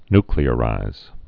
(nklē-ə-rīz, ny-)